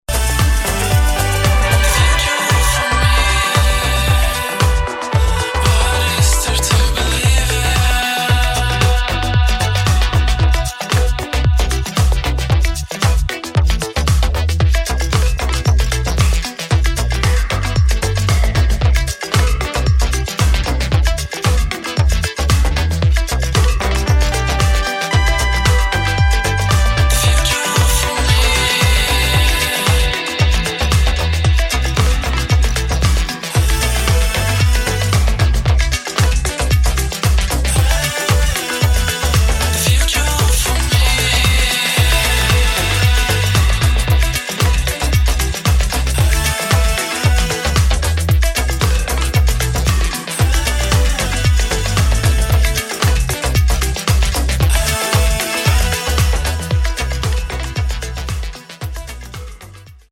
[ POP / ROCK / INDIE ]